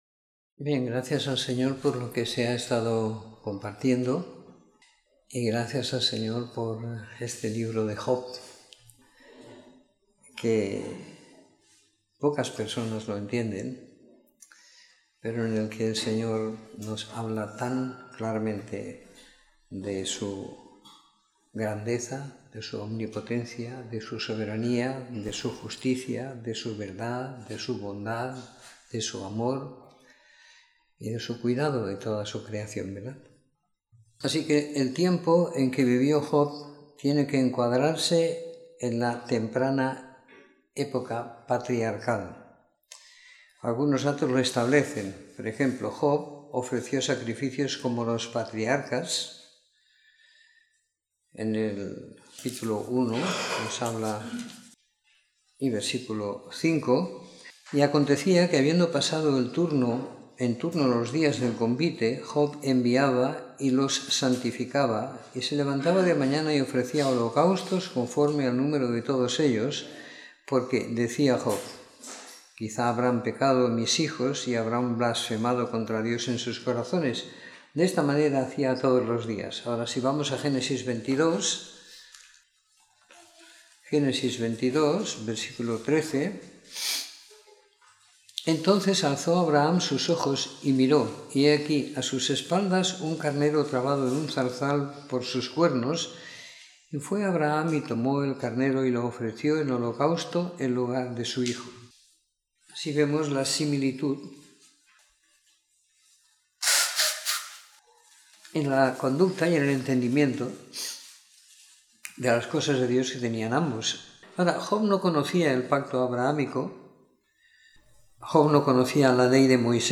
Comentario en el libro de Job siguiendo la lectura programada para cada semana del año que tenemos en la congregación en Sant Pere de Ribes.